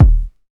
Kick Rnb 1.wav